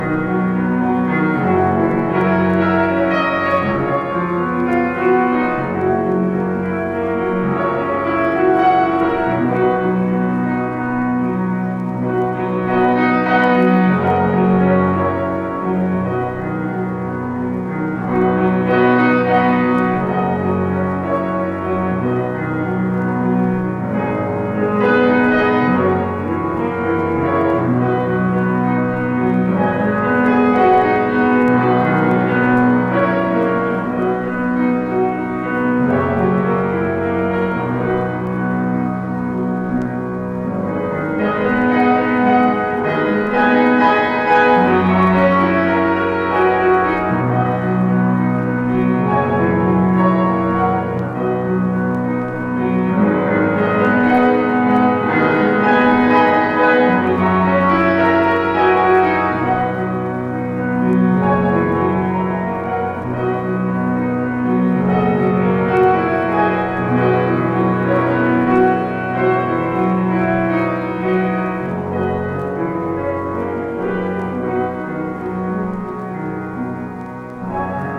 Este álbum é uma compilação de peças para piano